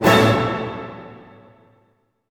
Index of /90_sSampleCDs/Roland LCDP08 Symphony Orchestra/HIT_Dynamic Orch/HIT_Orch Hit Maj
HIT ORCHM0DR.wav